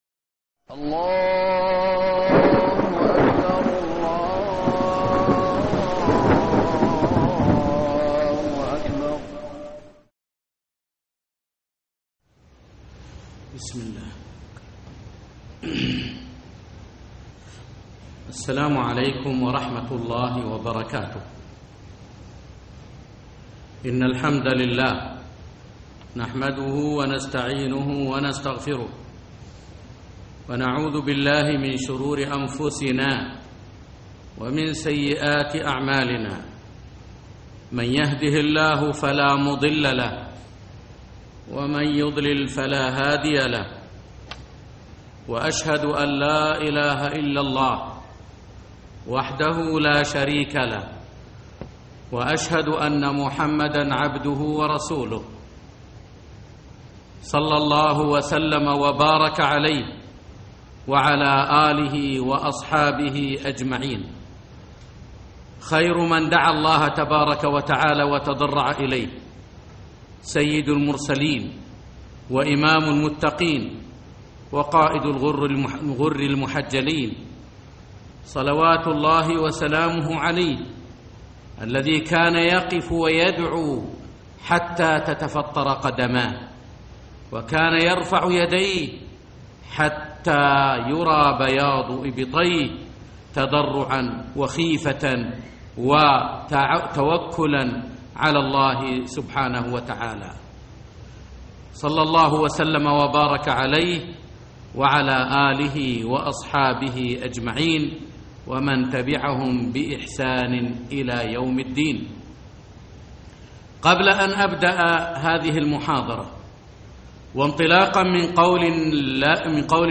محاضرة بعنوان الدعاء